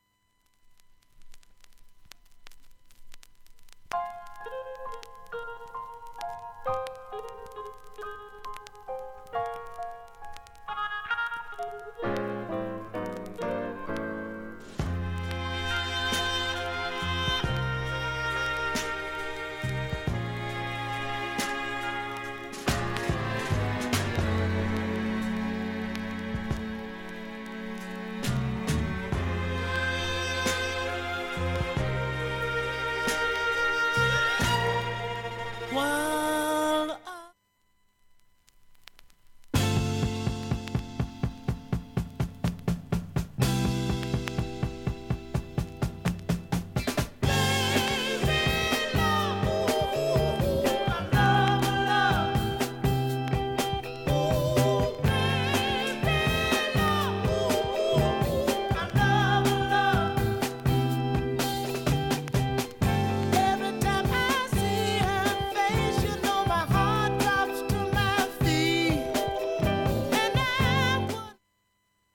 音質はいいと思います特にB面
B-1始め２０秒ほどかるいチリ出ます、
B-2始めにかすかなプツが１１回と２回出ます。